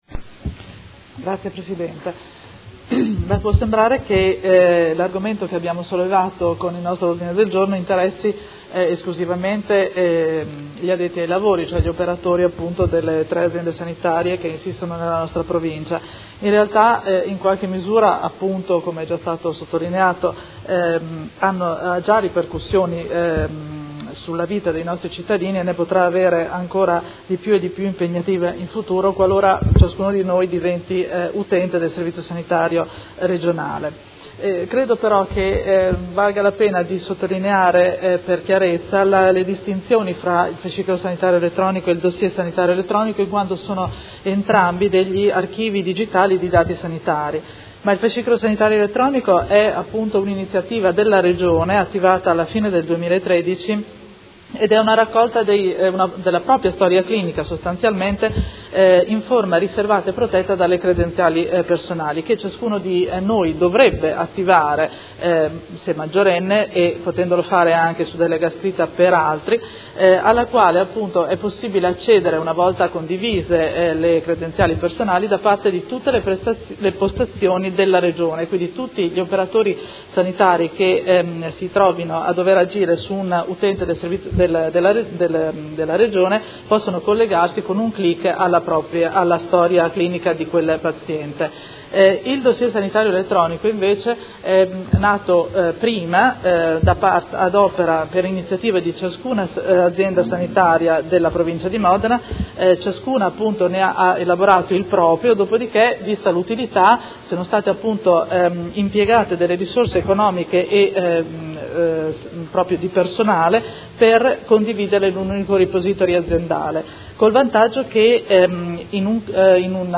Seduta del 12/05/2016. Dibattito su Ordine del Giorno presentato dai Consiglieri Trande, Arletti, Pacchioni, Poggi, Di Padova e Fasano (P.D.) avente per oggetto: Condivisione delle informazioni tra professionisti delle tre aziende sanitarie della provincia di Modena: in attesa che tutti abbiano il Fascicolo Sanitario Elettronico va individuata una soluzione transitoria che ripristini in tempi brevi la condivisione ed emendamento